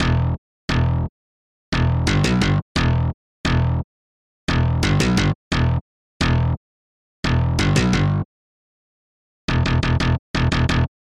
重型合成器低音
描述：欢快的合成器低音循环
Tag: 87 bpm Hip Hop Loops Bass Loops 950.47 KB wav Key : E